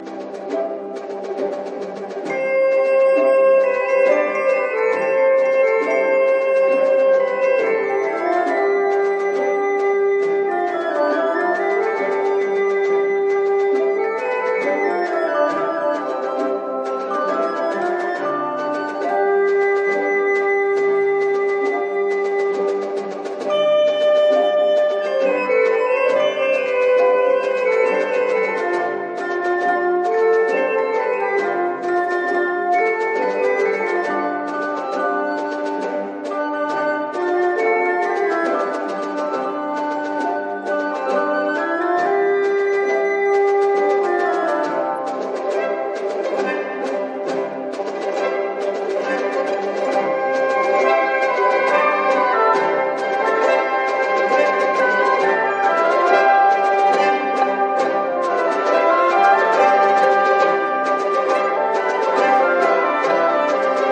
Tempo di Bolero moderato assai